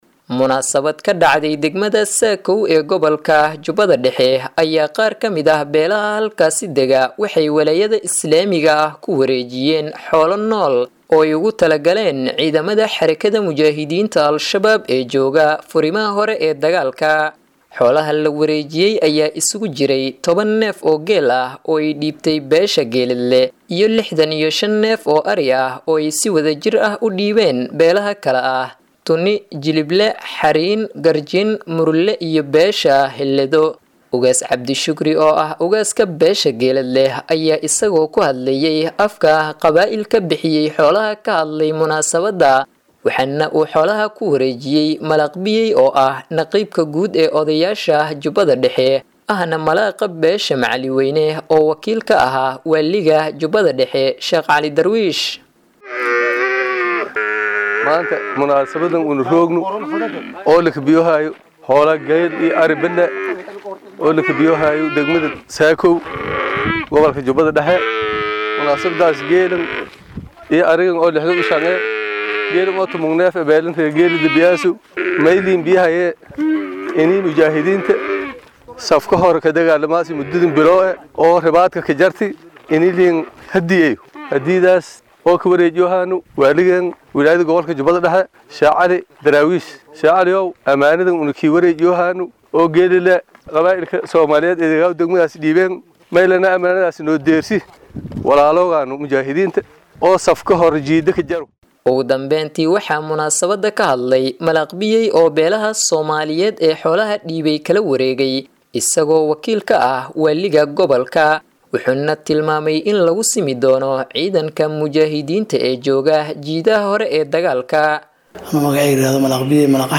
Ugu horeyn waxaa munaasabadda ka hadlay qaar kamid ah odayaasha qabaa’ilka bixiyay ariga, waxayna sheegeen sababta ku riixday iney bixiyaan xoolahan.